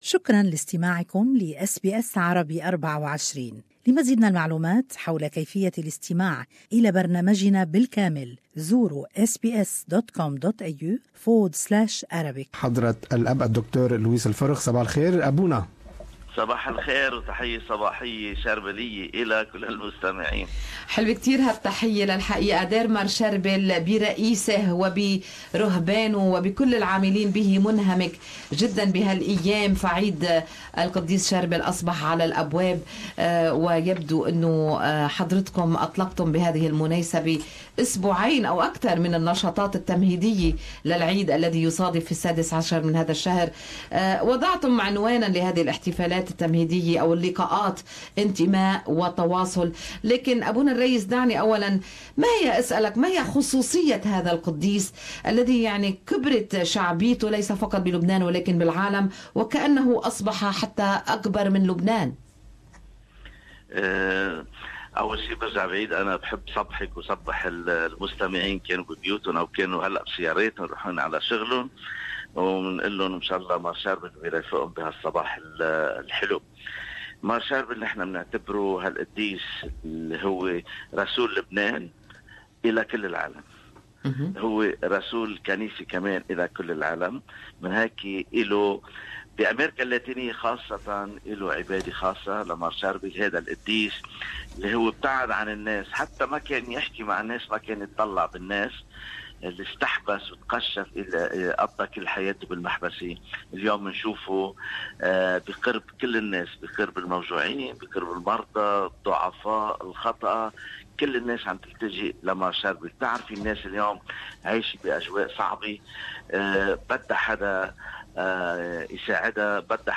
What is the peculiarity of this saint, whose popularity has grown not only in Lebanon but also in the world? Good Morning Australia interviewed